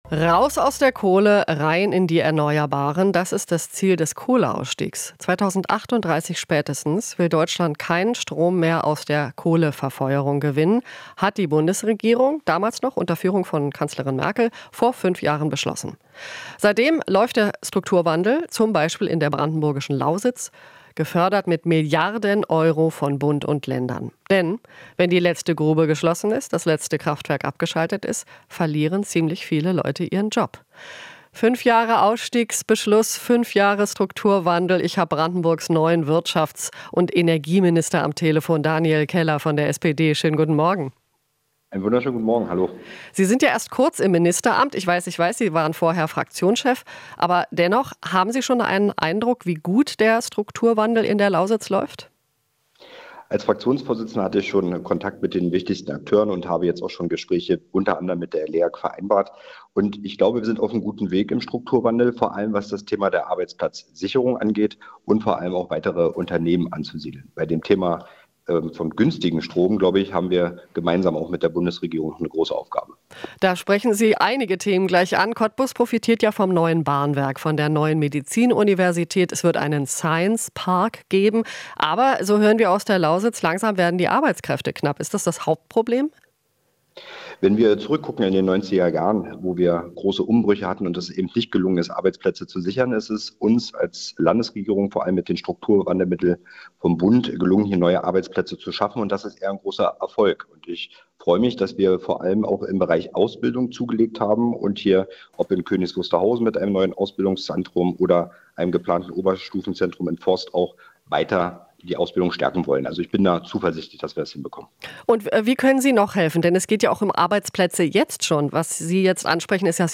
Interview - Minister Keller (SPD) sieht Strukturwandel auf gutem Weg
Das gelte vor allem für die Sicherung von Arbeitsplätzen und die Ansiedlung neuer Betriebe, sagte der SPD-Politiker am Donnerstag im rbb24 Inforadio.